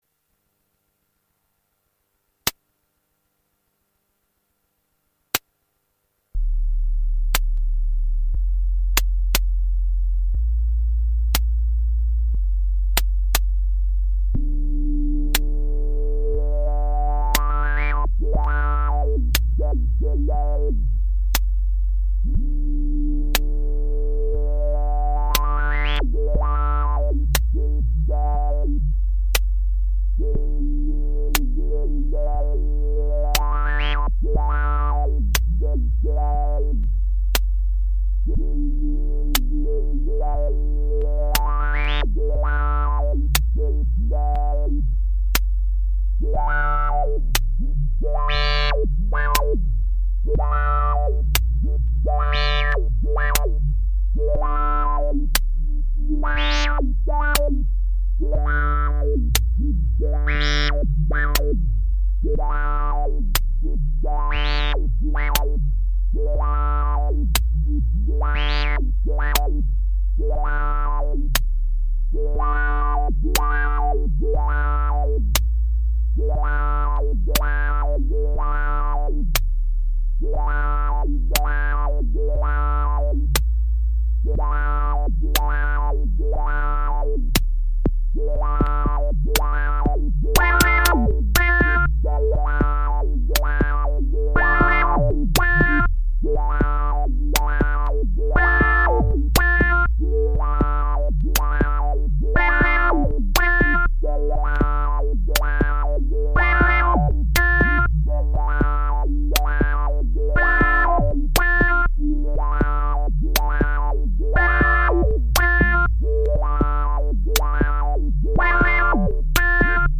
Enter" Dance Track
low bass use good headphones